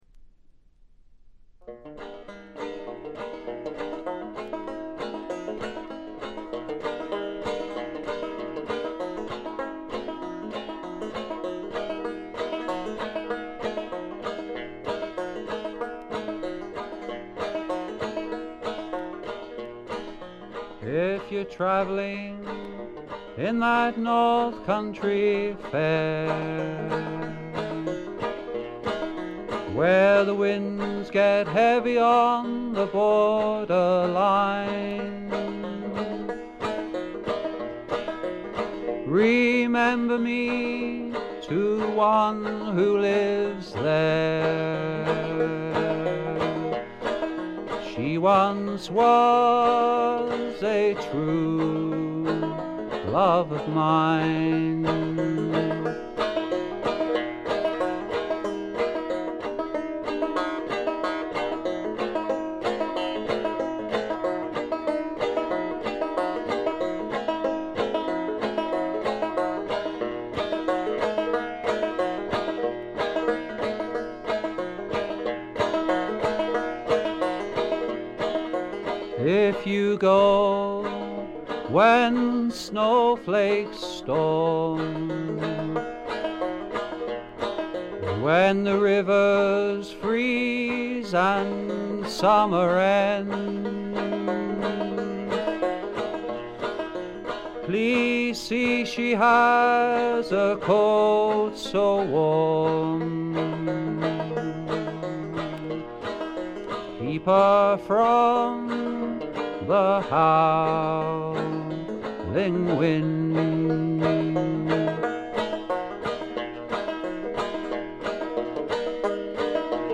基本はバンジョーをバックにした弾き語りで、訥々とした語り口が染みる作品です。
試聴曲は現品からの取り込み音源です。